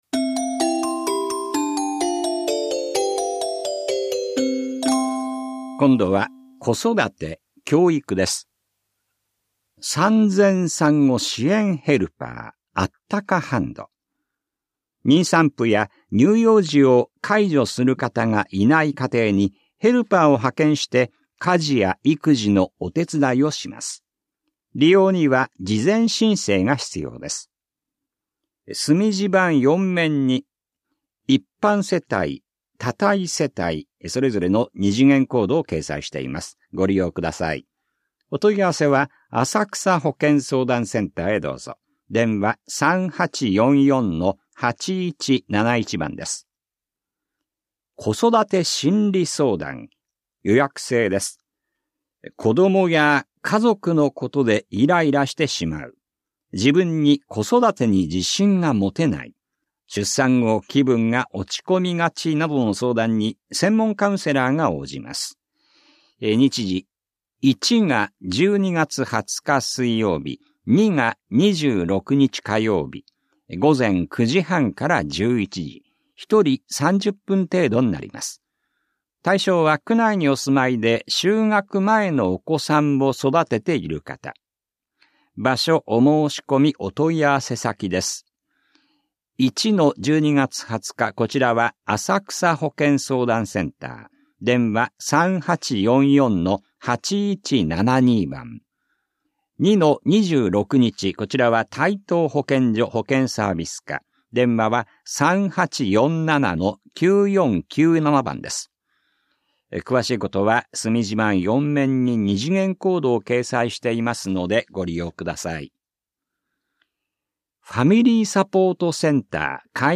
広報「たいとう」令和5年11月20日号の音声読み上げデータです。